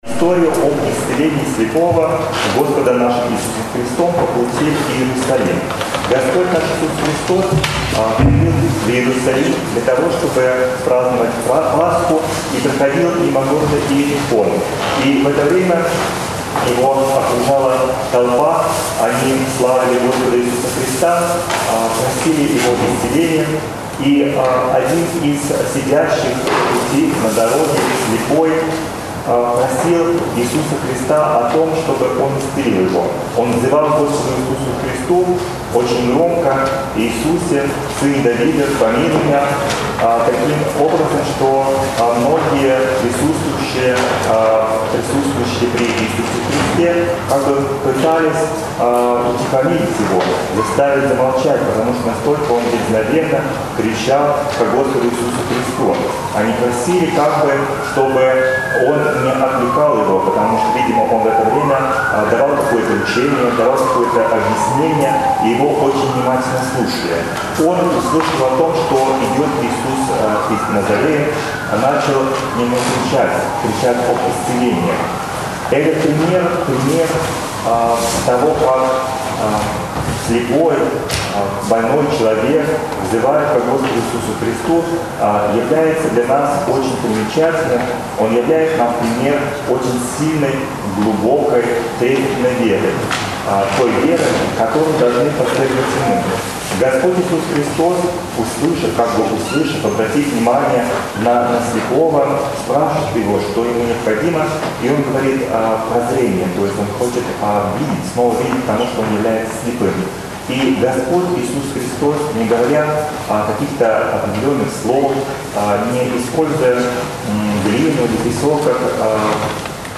Седмица 17-я по Пятидесятнице. Проповедь